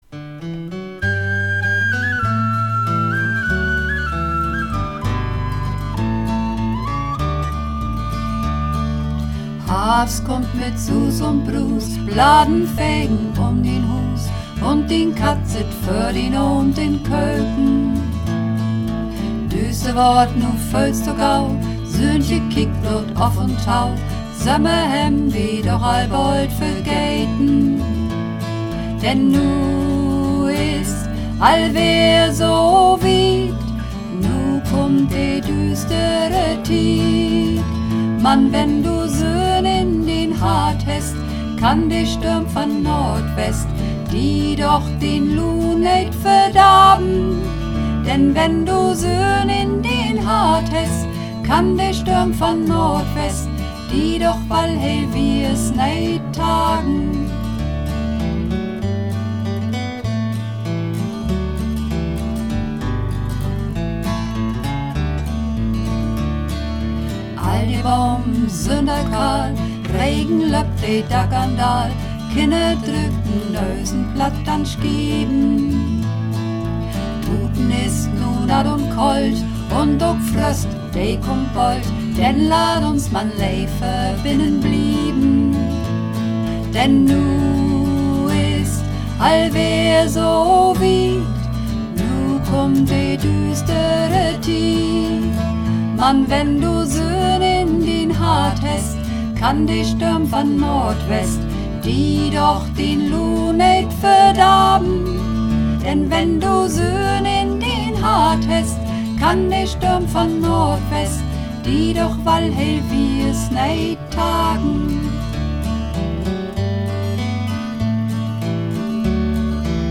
Harvst (Mehrstimmig)
Harvst__3_Mehrstimmig.mp3